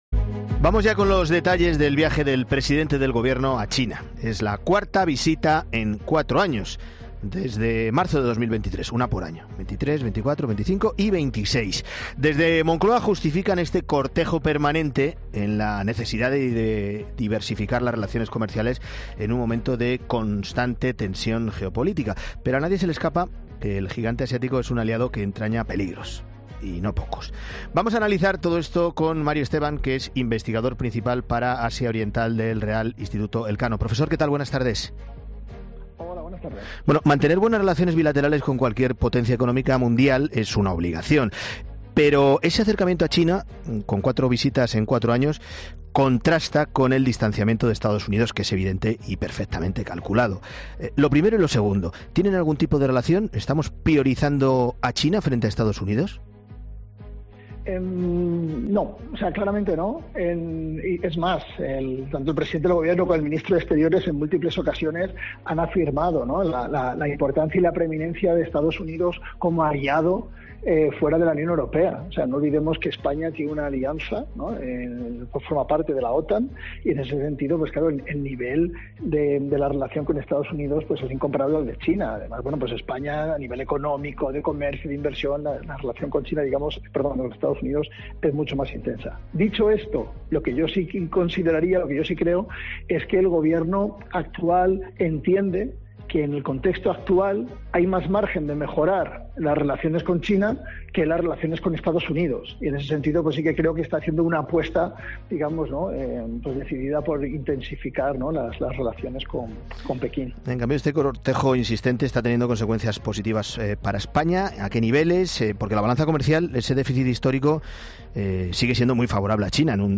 El presidente del Gobierno, Pedro Sánchez, ha emprendido su cuarto viaje a China en cuatro años, una frecuencia que desde Moncloa justifican por la necesidad de diversificar las relaciones comerciales en un momento de tensión geopolítica.